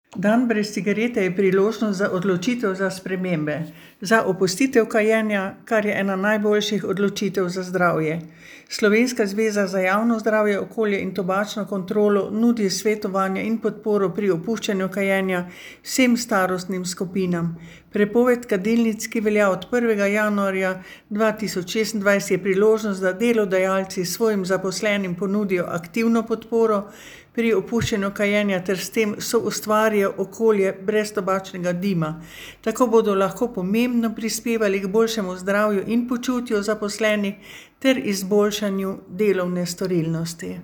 Avdio izjave: